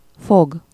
Ääntäminen
IPA: /foɡ/